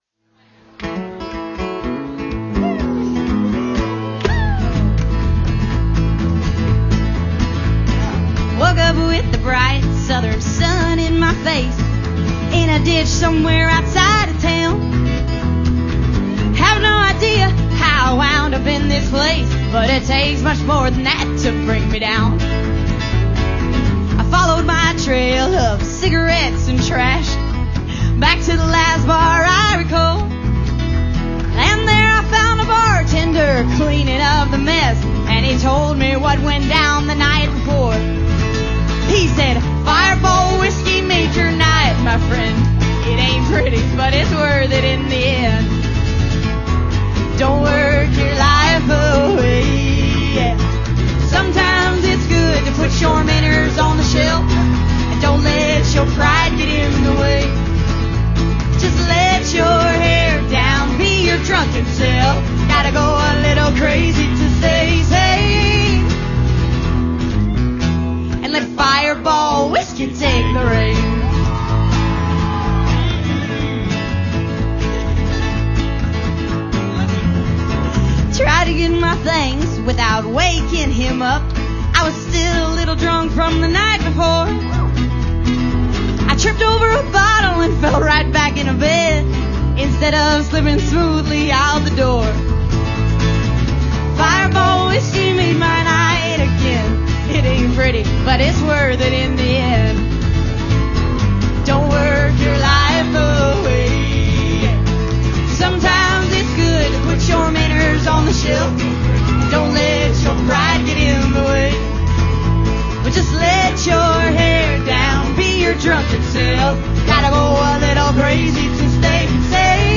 Live from Buck Owen's Crystal Palace